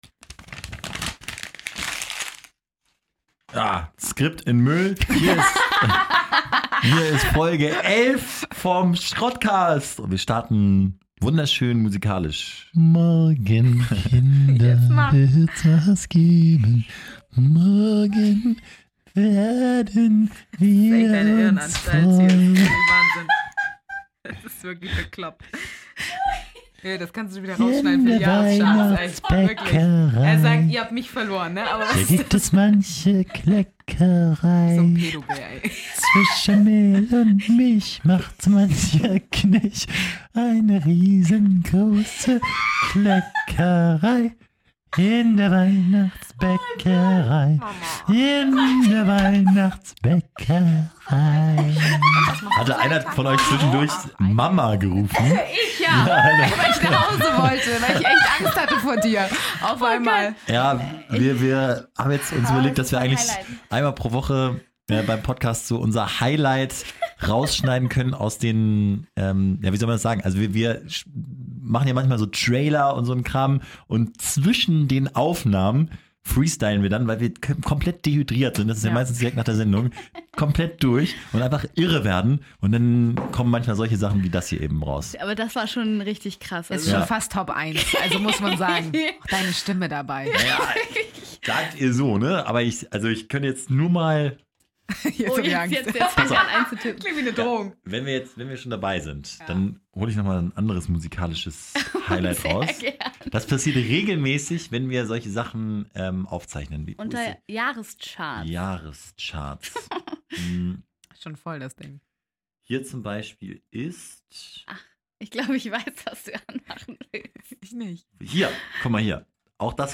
Der Titel deutet es schon an: Heute haben wir uns mal wieder selbst unterboten. Ganz schwere Kost: Es gibt gruseligen Weihnachtsgesang und "deepe" Themen wie künstliche Intelligenz und den Weltuntergang in genau 60 Jahren.